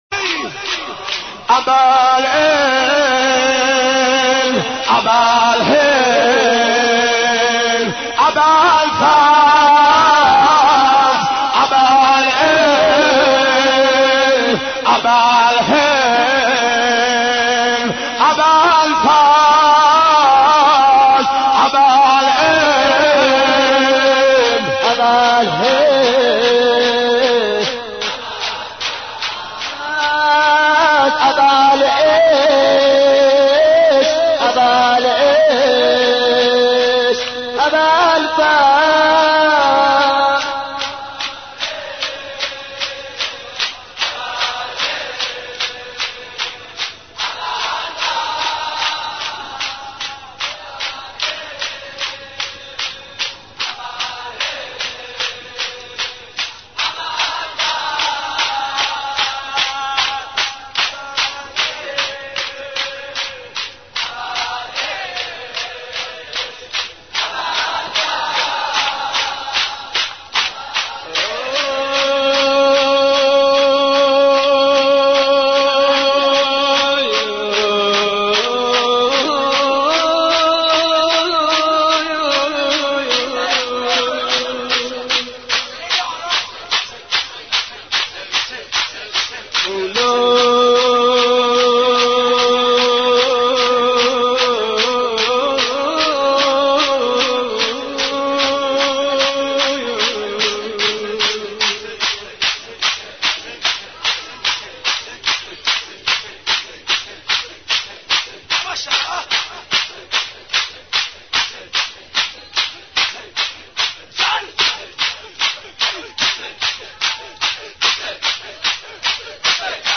حضرت عباس ع ـ شور 23